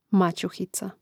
mȁćuhica maćuhica